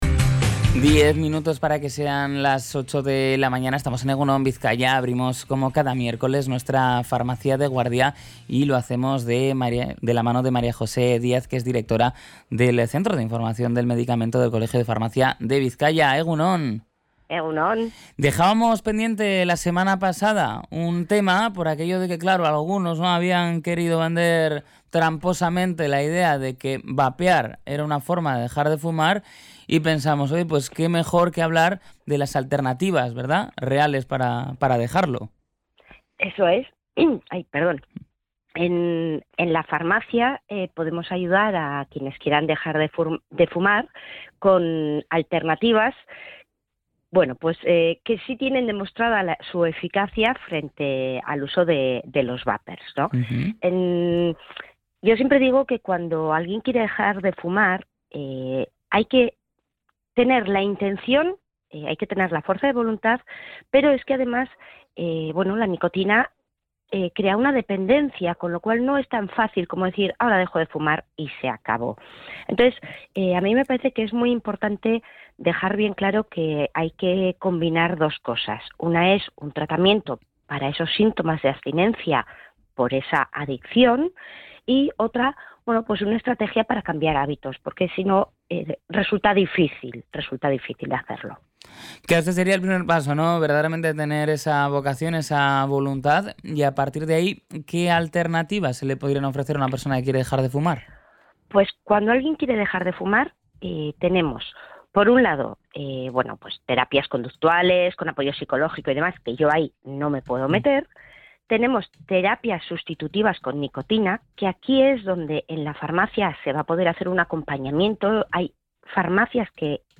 En la última edición de la sección «Farmacia de Guardia» en el programa EgunOn Bizkaia, se ha abordado un reto de salud pública fundamental: el abandono del tabaquismo.